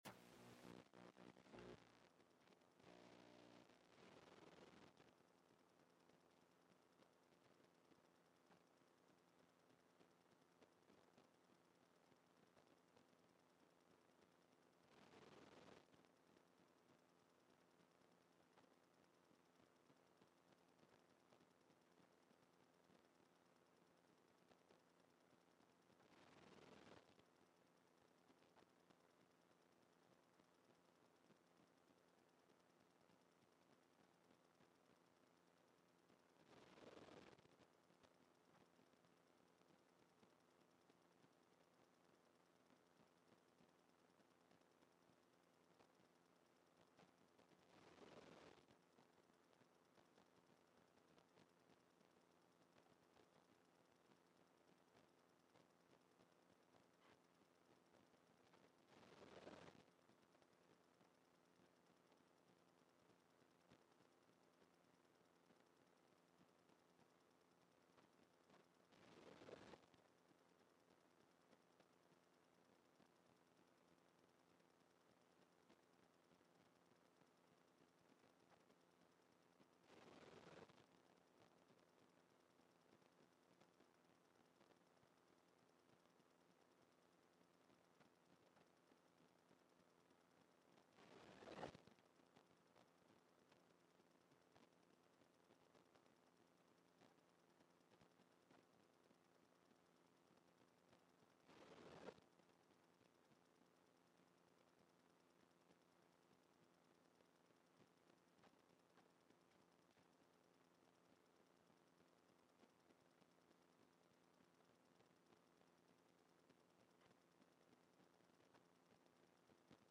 Sermons by Passion Community Church